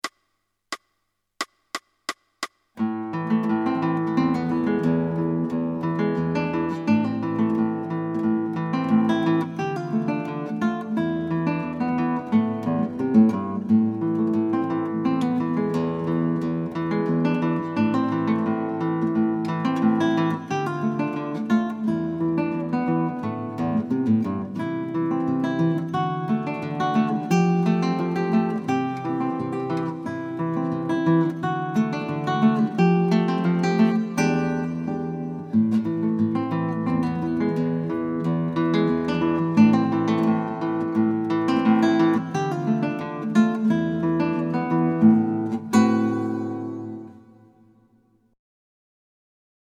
• Thema: Fingerpicking, Folk, Country, Ragtime
• Instrumenten: Gitarre Solo